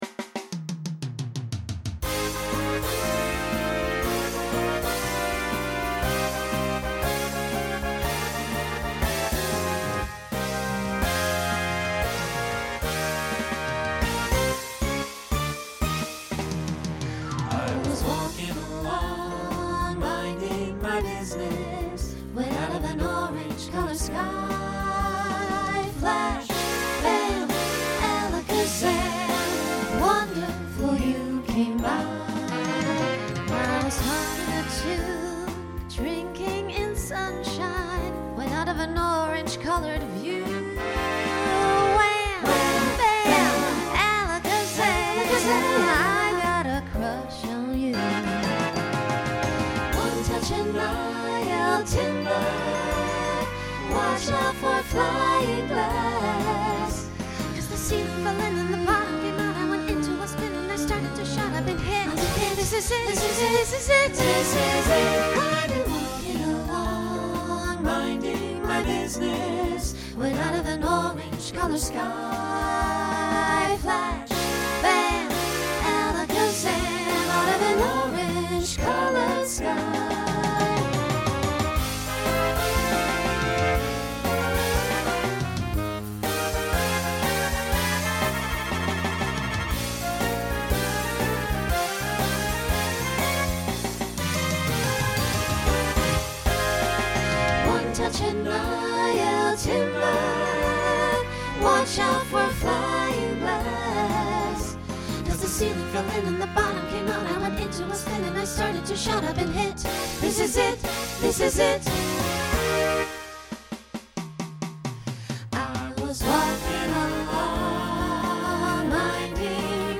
Voicing SATB Instrumental combo Genre Swing/Jazz
Mid-tempo , Solo Feature